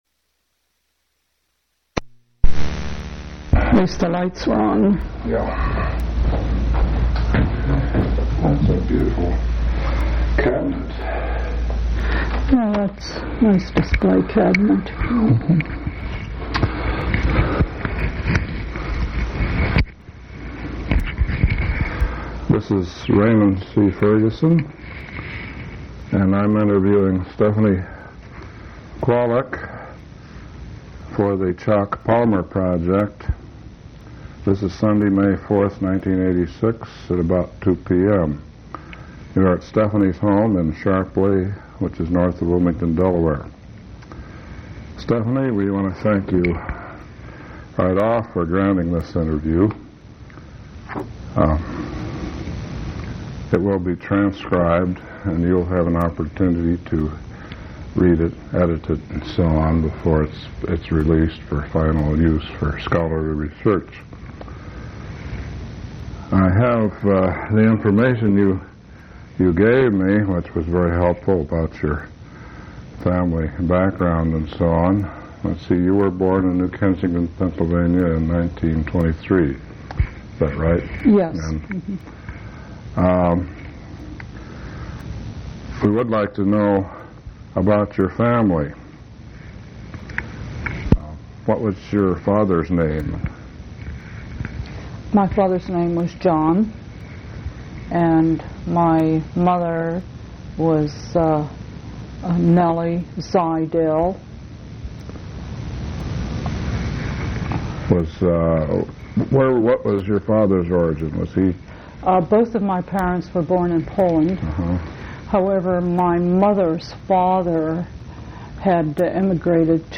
Place of interview Delaware--Sharpley
Genre Oral histories